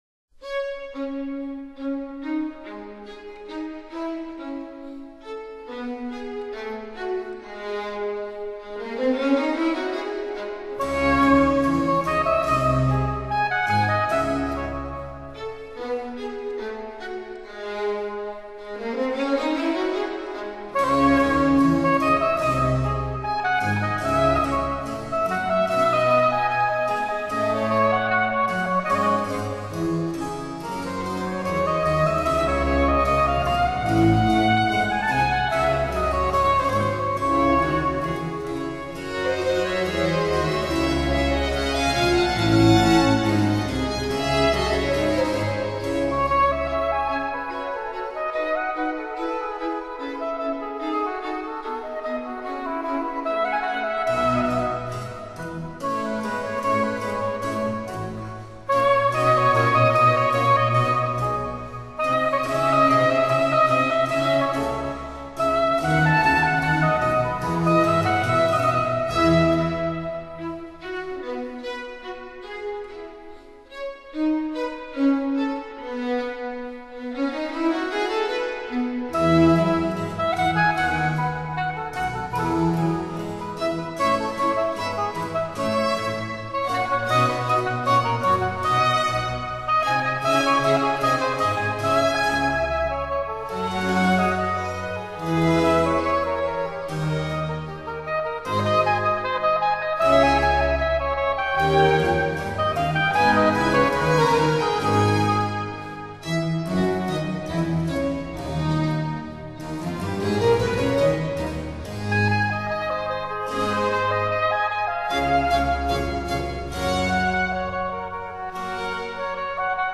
Concerto for oboe, strings & continuo in D minor